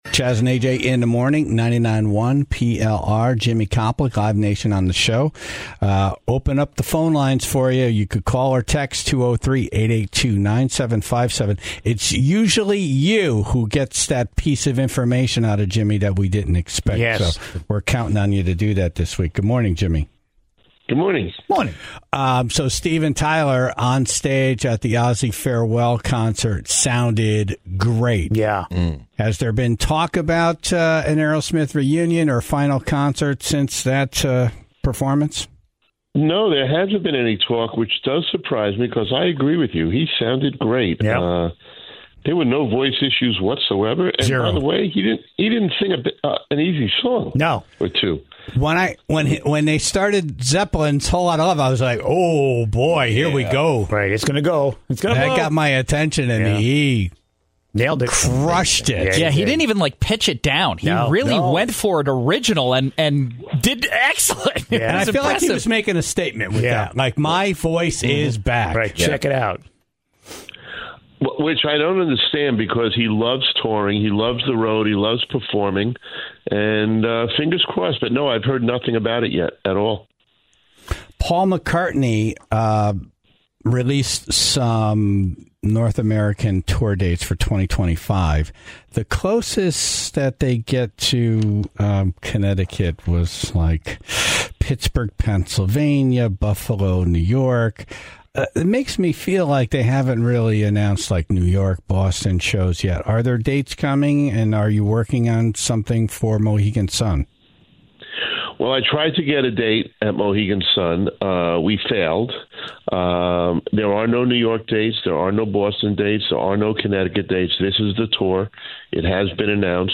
Plus, the Tribe called in their questions, and got some exciting news regarding Kenny Chesney, Nickleback and Guns N Roses.